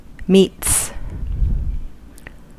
Ääntäminen
Ääntäminen US Tuntematon aksentti: IPA : /miːts/ Haettu sana löytyi näillä lähdekielillä: englanti Käännöksiä ei löytynyt valitulle kohdekielelle.